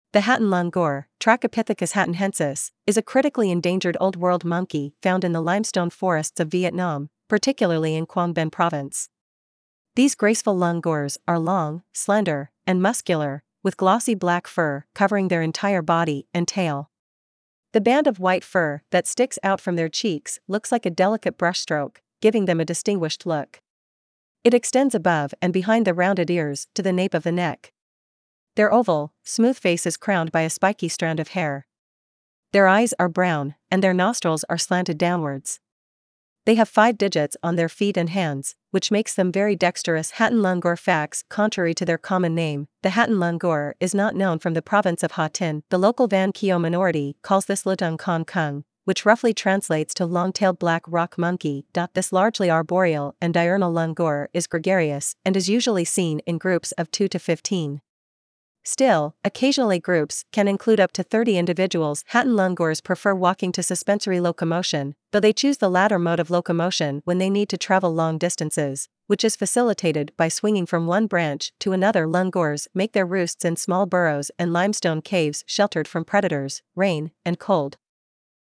Hatinh Langur
Hatinh-langur.mp3